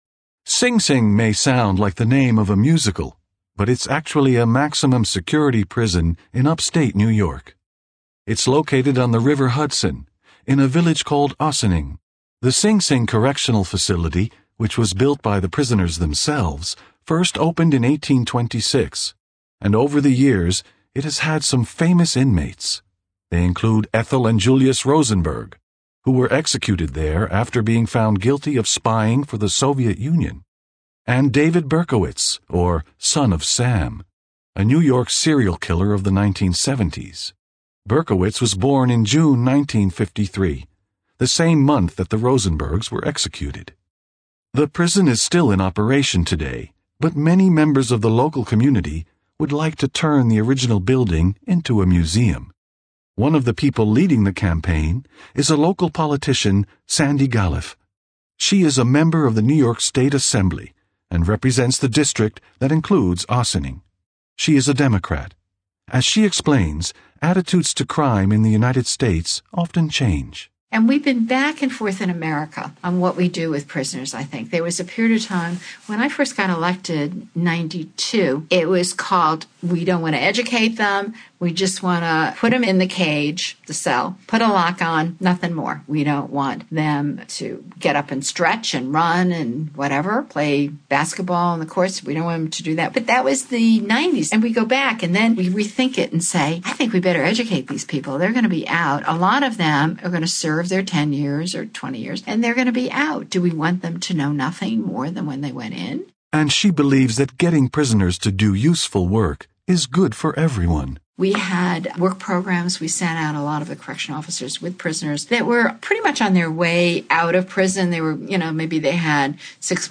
Interview: rehabilitation